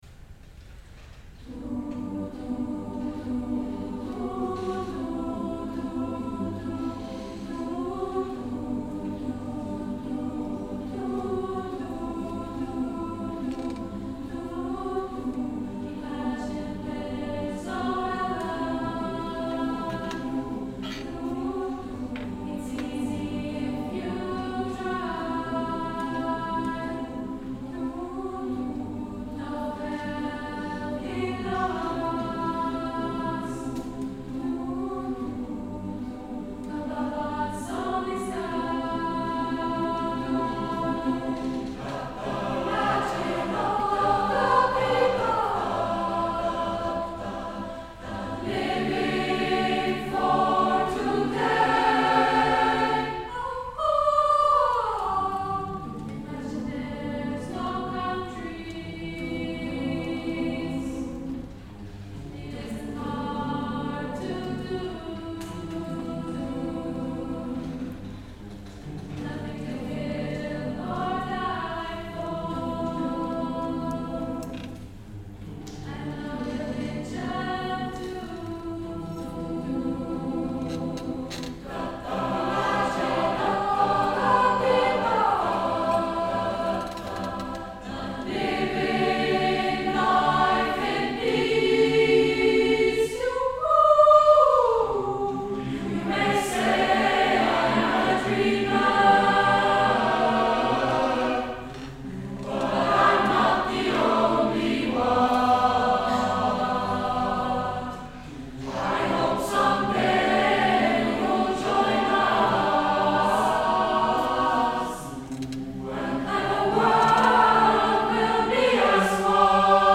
MATURCHOR 2025